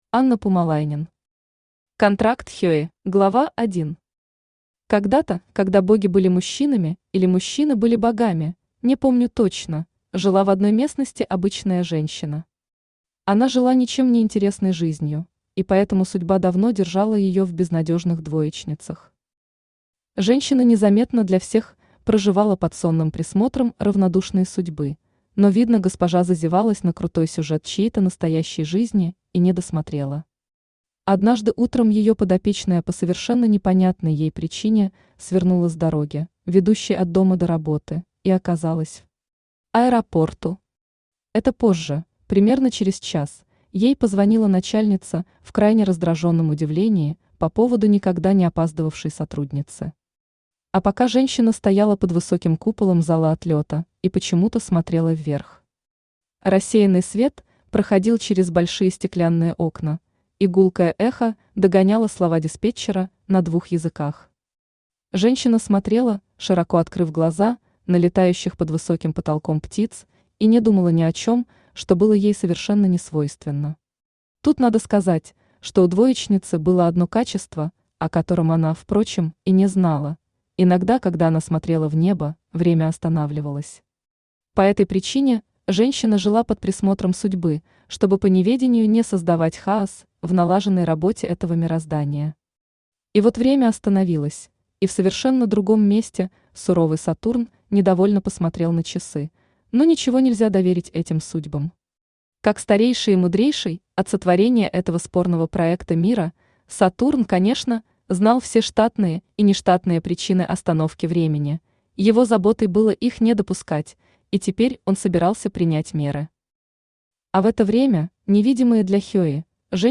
Aудиокнига Контракт Хеи Автор Анна Пумалайнен Читает аудиокнигу Авточтец ЛитРес.